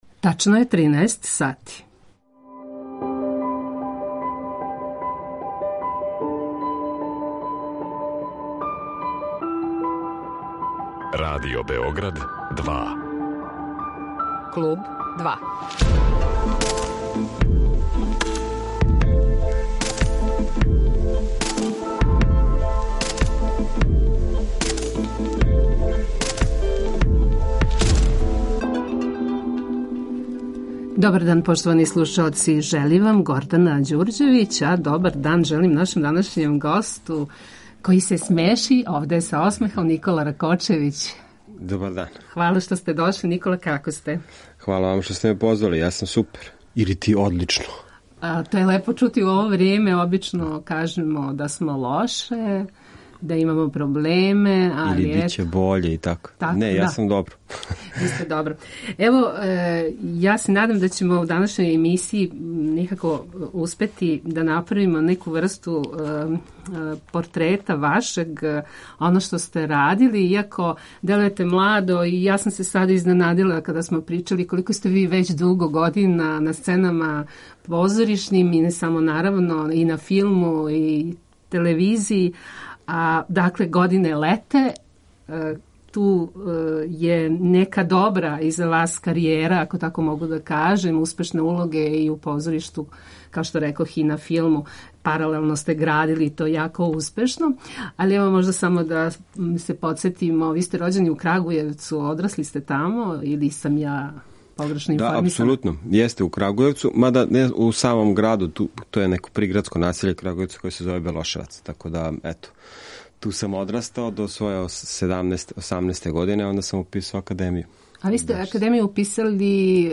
Гост Kлуба 2 је позоришни, филмски и телевизијски глумац Никола Ракочевић